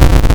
damage_taken.wav